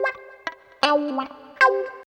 95 GTR 2  -R.wav